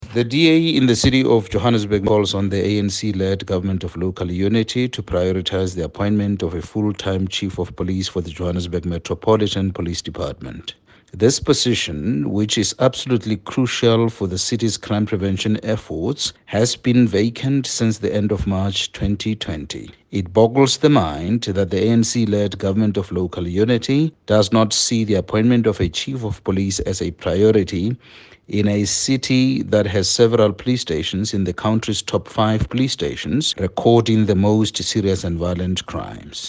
Note to Editors: Please find an English soundbite by Cllr Solomon Maila